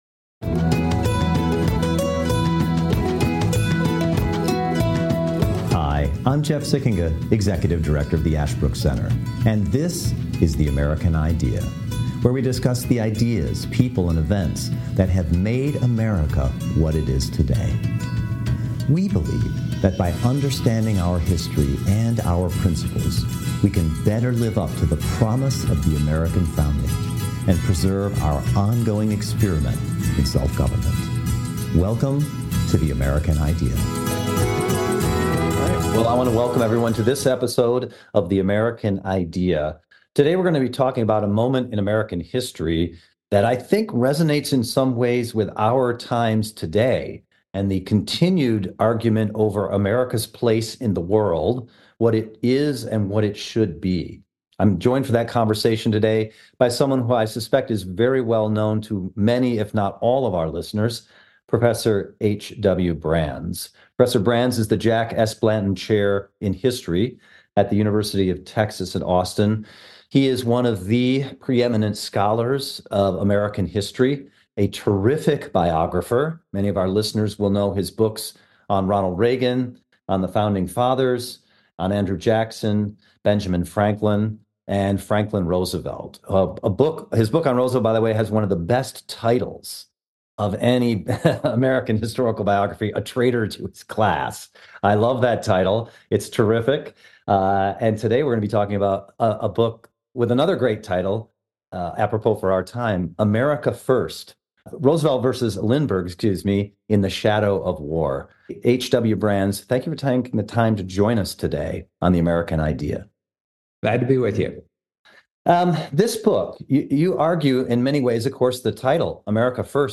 The American Idea explores America's Founding principles and their effect on American history and government. Through thoughtful conversations with renowned academics and public figures from across the country, we examine the history and political thought behind our country’s greatest documents and debates, as well as contemporary issues, American popular culture, and political statesmanship.